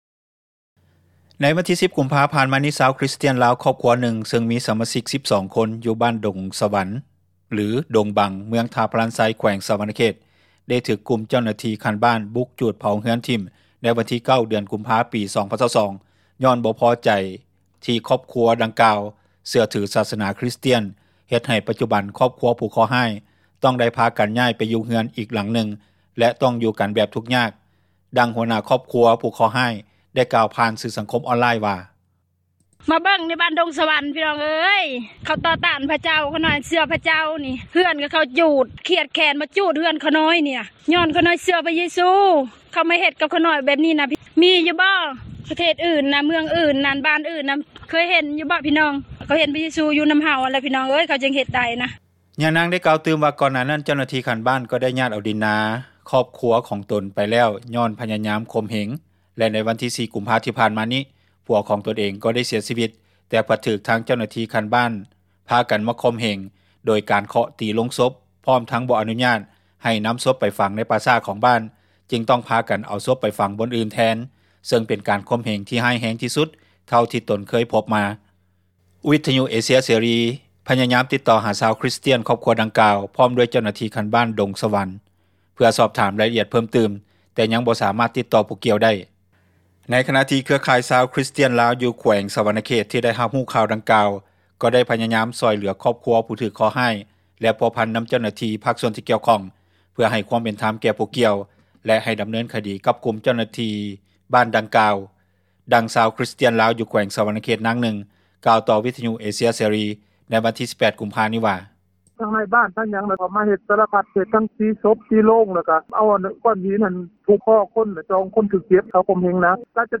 ດັ່ງຊາວຄຣິສຕຽນລາວ ຢູ່ແຂວງສວັນນະເຂດ ນາງນຶ່ງກ່າວຕໍ່ວິທຍຸເອເຊັຽເສຣີ ໃນວັນທີ 18 ກຸມພານີ້ວ່າ: